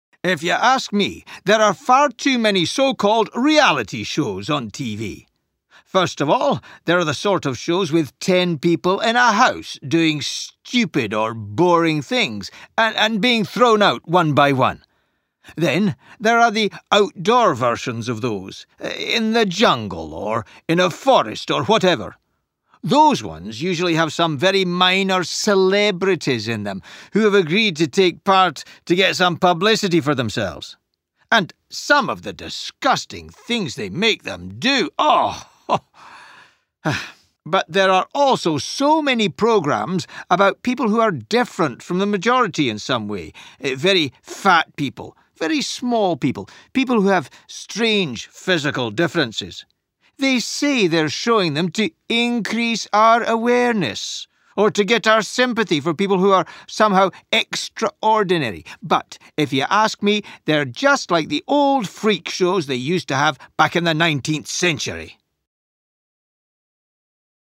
This activity provides short listening practice based around a monologue regarding reality TV in the UK. The monologue provides observations of reality TV.